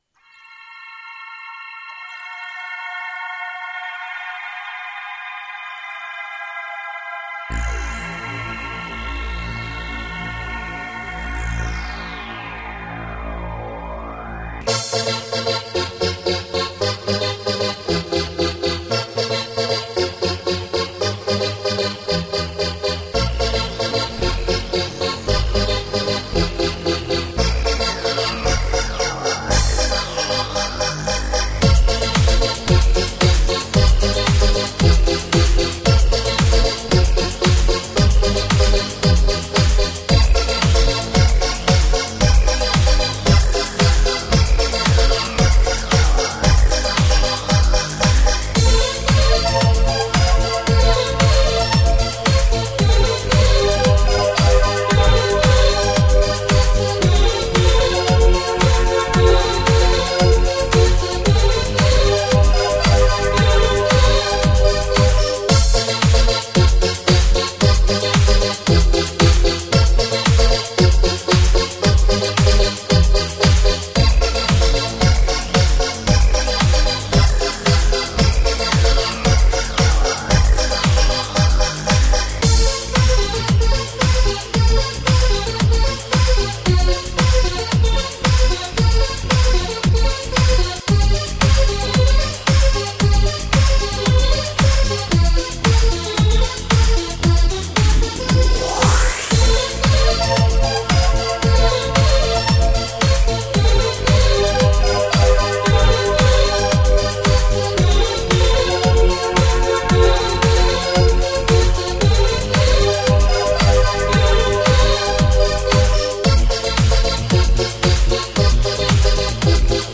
Взял свой старенький корг м1 и наиграл что в голову пришло. (Не судите строго, вырос на spacesynth...)